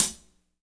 -16  HAT 5-L.wav